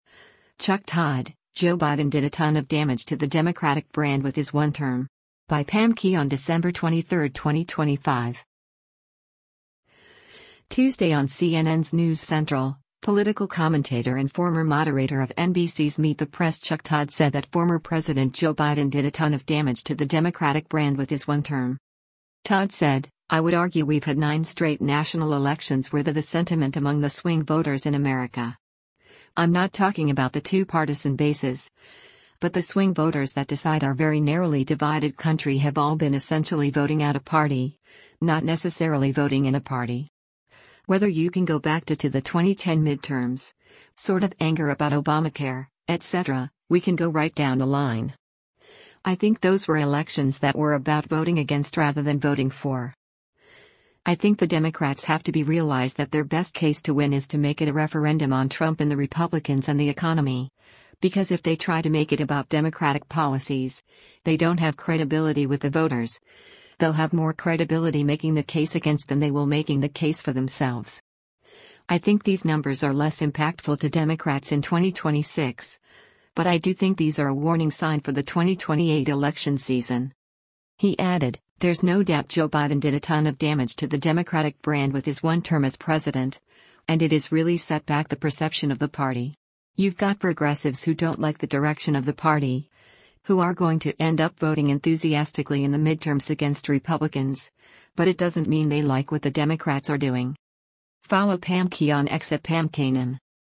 Tuesday on CNN’s “News Central,” political commentator and former moderator of NBC’s “Meet the Press” Chuck Todd said that former President Joe Biden “did a ton of damage to the Democratic brand with his one term.”